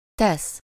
Ääntäminen
IPA: /tɛs/